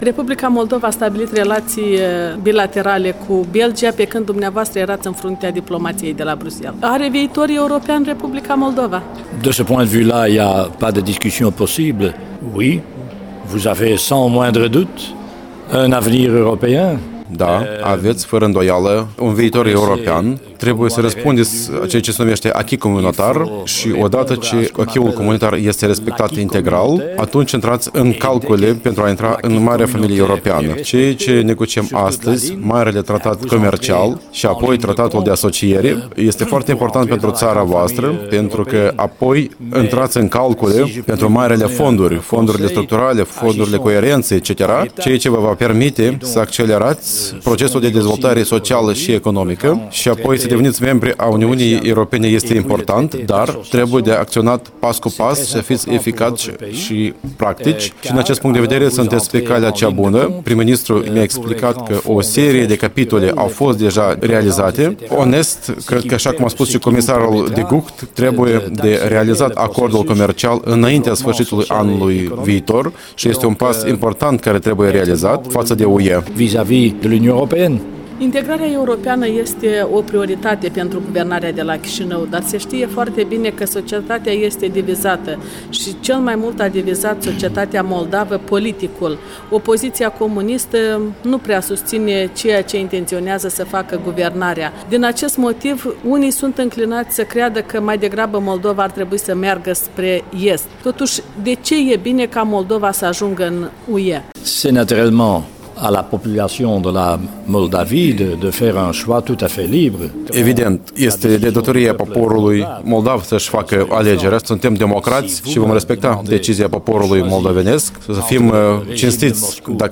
Interviu cu Willy Claes, fost secretar-general al NATO.